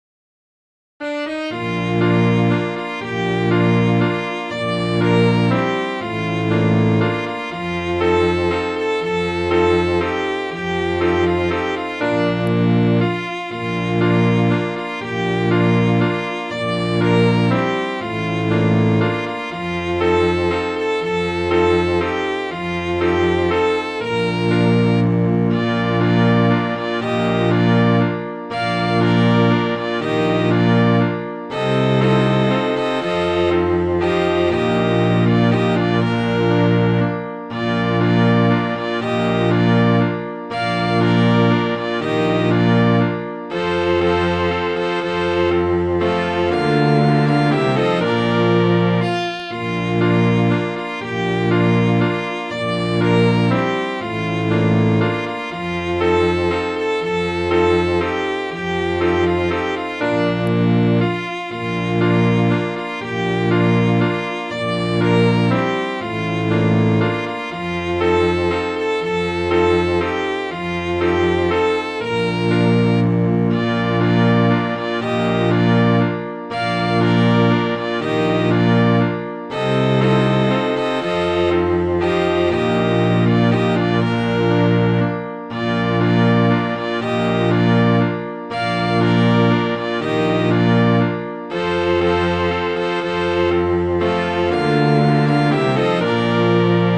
Midi File, Lyrics and Information to Dear Evelina, Sweet Evelina